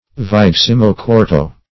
Vigesimo-quarto \Vi*ges"i*mo-quar"to\, n.; pl. -tos.